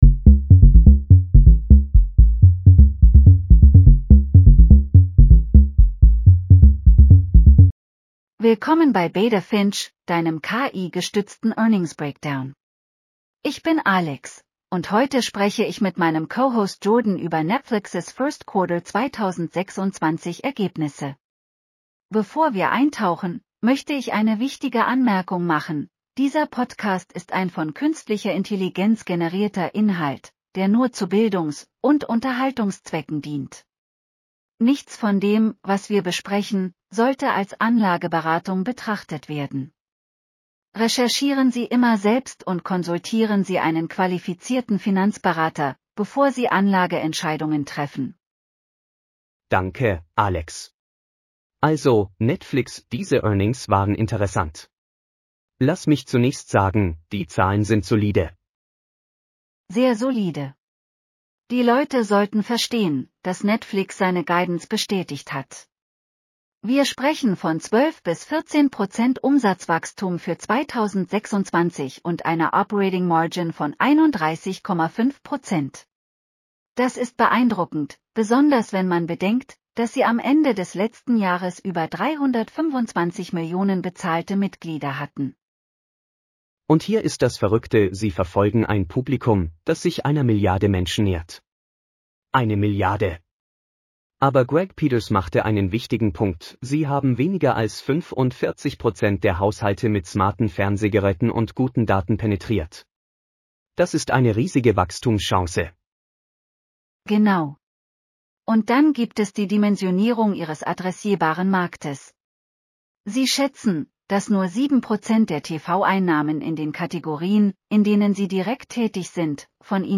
Netflix Q1 2026 earnings call breakdown.